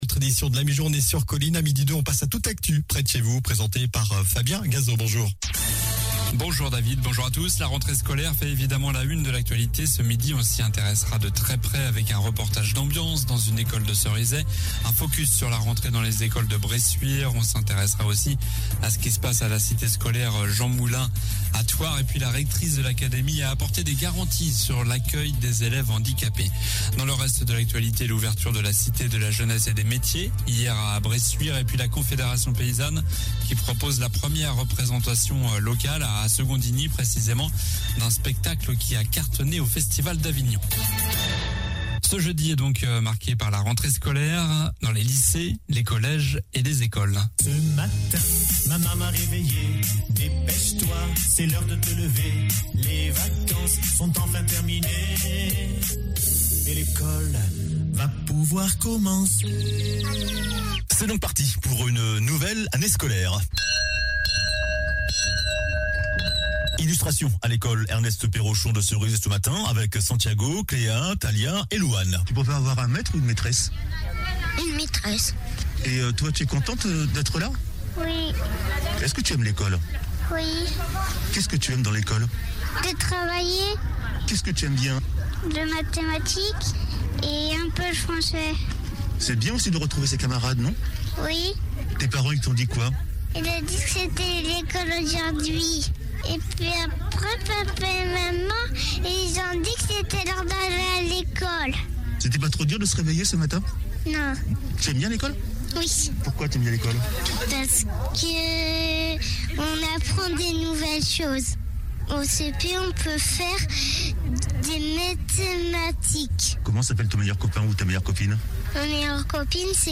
Journal du jeudi 02 septembre (midi)